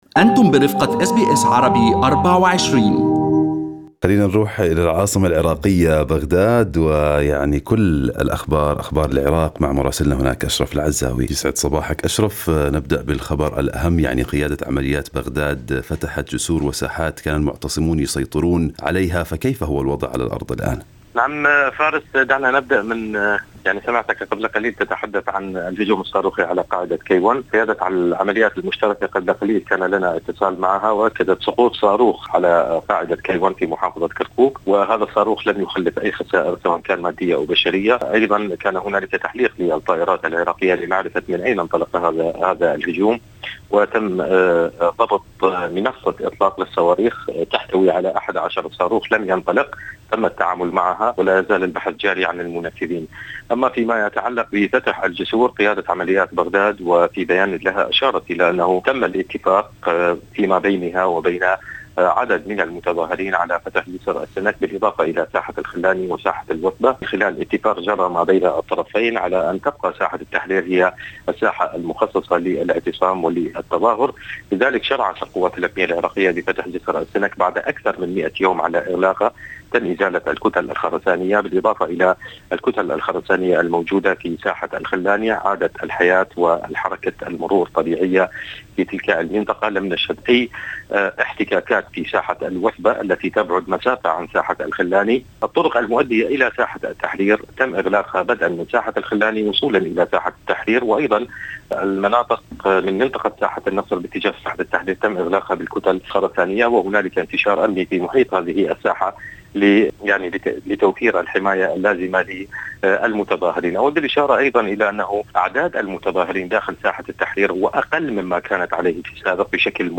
تقرير